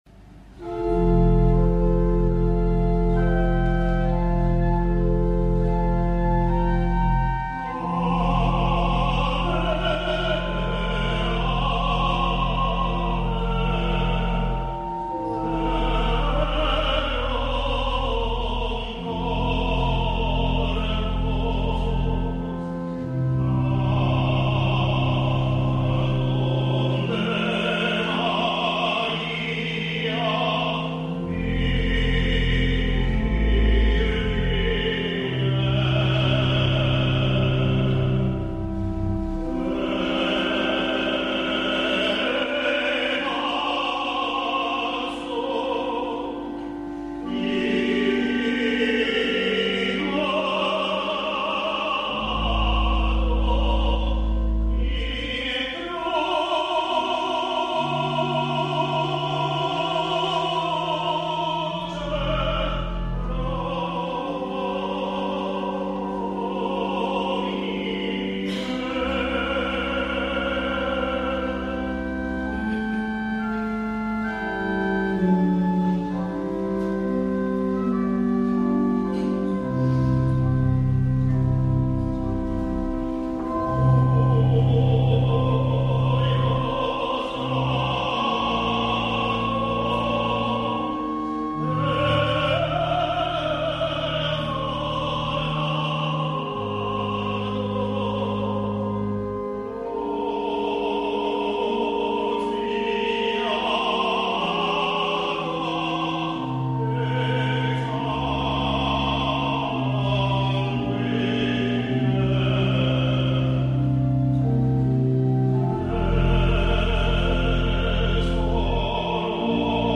(ten. organo)